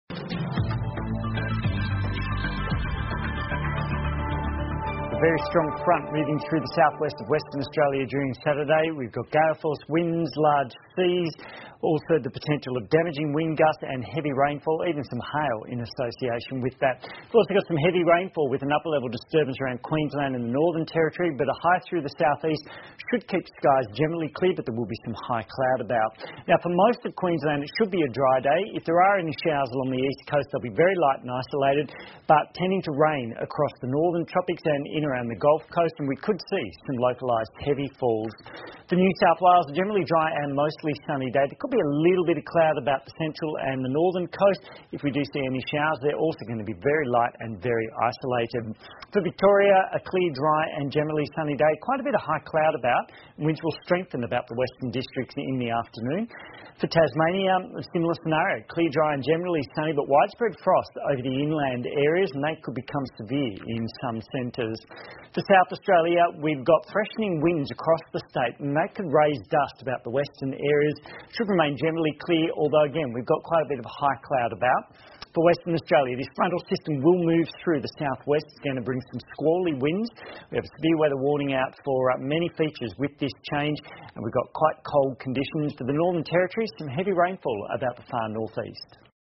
澳洲新闻 (ABC新闻快递) 2016-05-03 听力文件下载—在线英语听力室